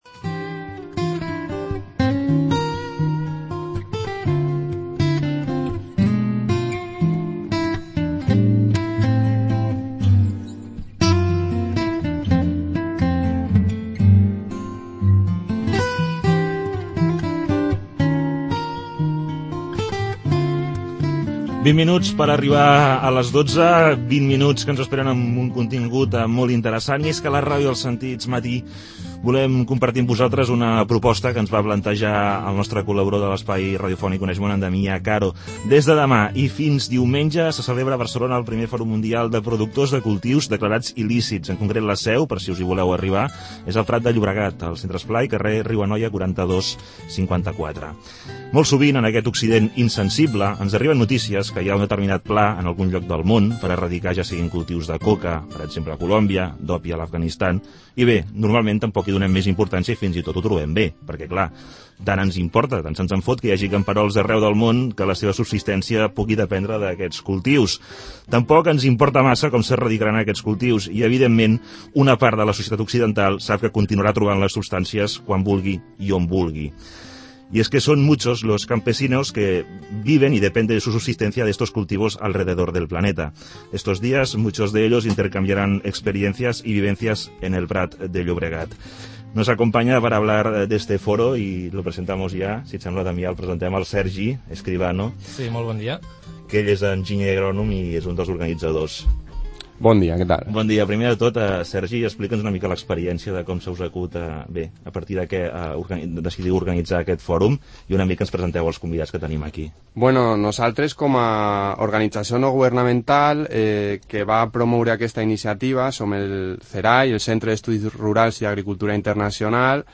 Escucha la ENTREVISTA sobre las conclusiones del FMPCDI También puedes escuchar la presentación radiofónica del Foro, en Radio 4 - La radio de los sentidos.